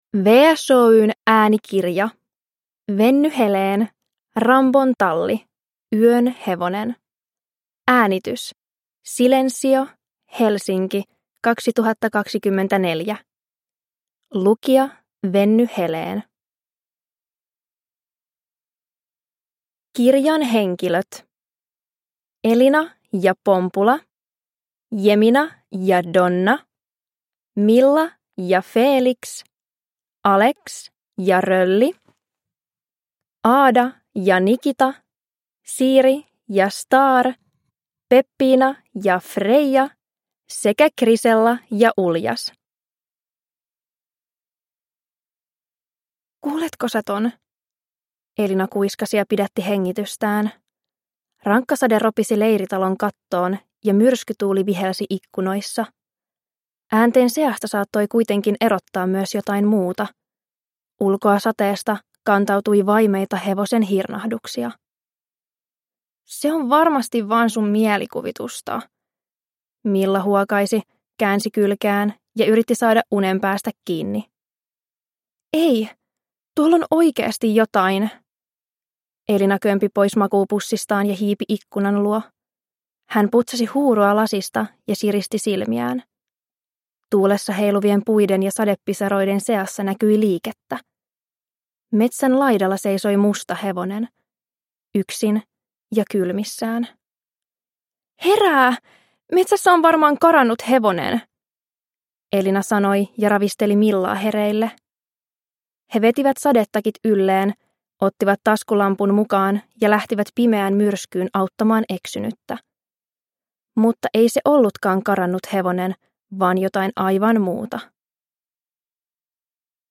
Rambon talli: Yön hevonen – Ljudbok